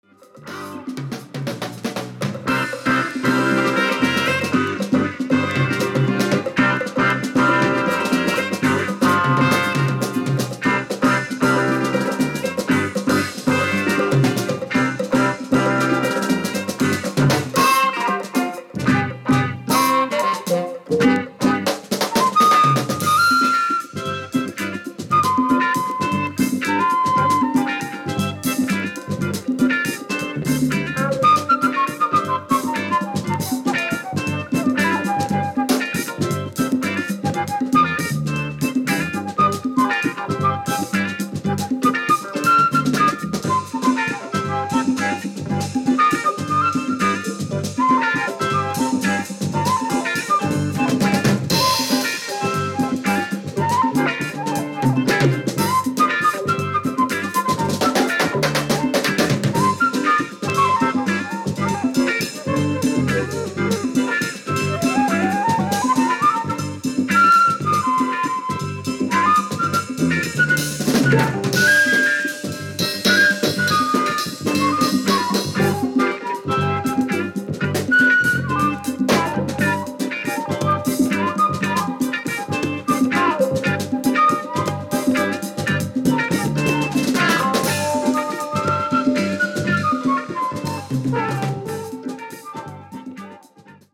Guitar, Keyboards
Percussion, Flute, Vocals
African Drums,Vocals, Piano